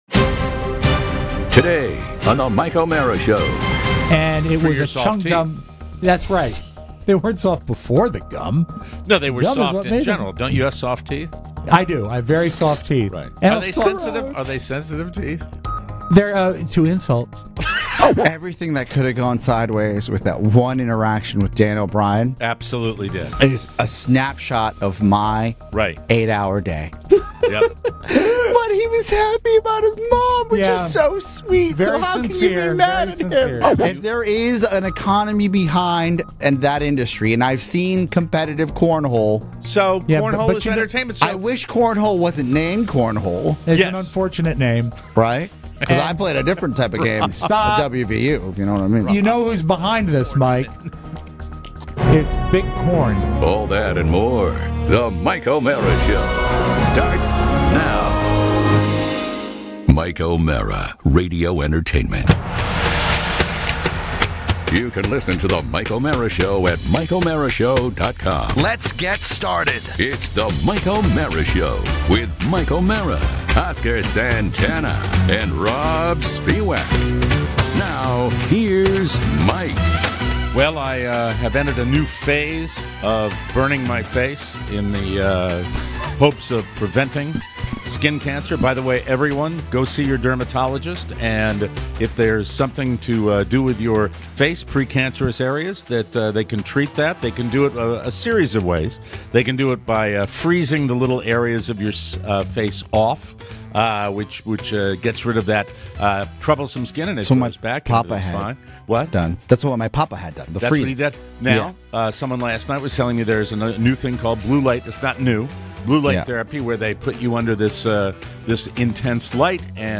#3218: Live! In Studio!